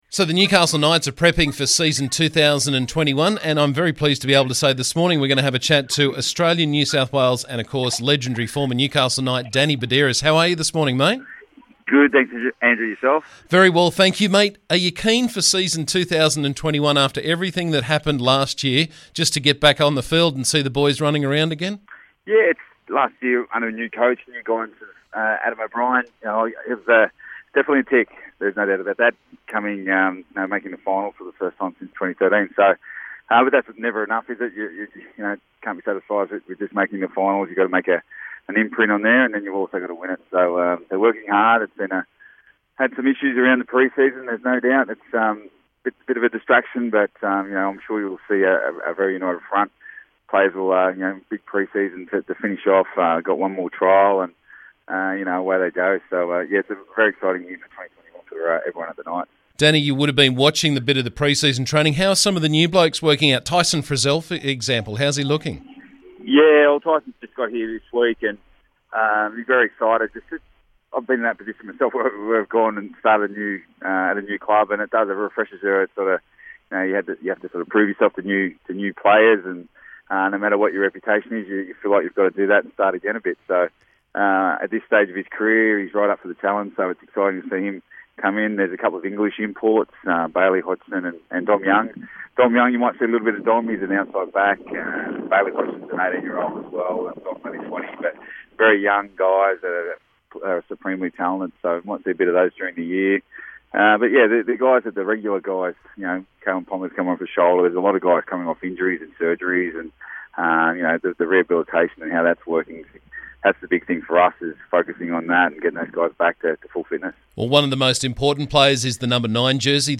Australian, NEw South Wales and Newcastle legend Danny Buderus joined us to talk about the Knights preseason and how things are shaping up for the new year.